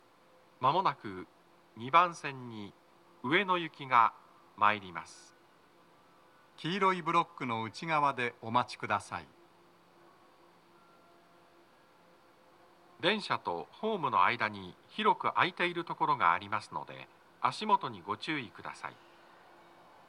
スピーカー種類 TOA天井型
🎵接近放送
鳴動は、やや遅めでした。
男声